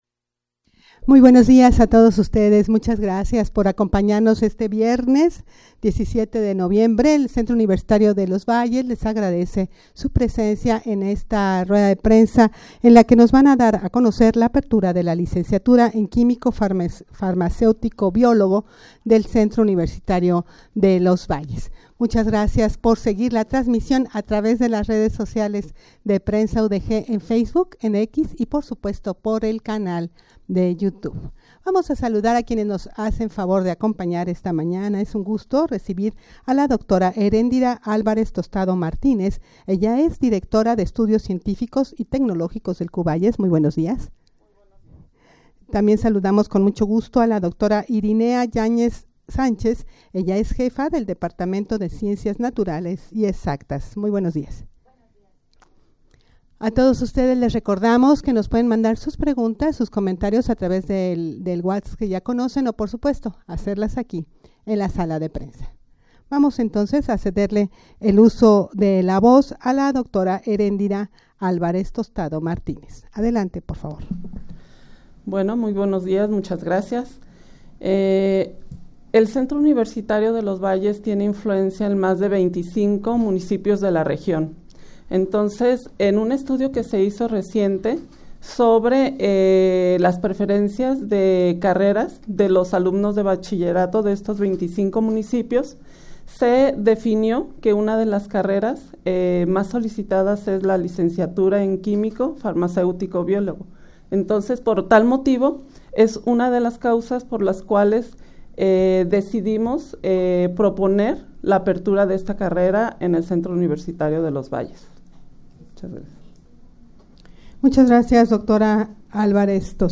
Audio de la Rueda de Prensa
rueda-de-prensa-para-dar-a-conocer-la-apertura-de-la-licenciatura-en-quimico-farmaceutico-biologo.mp3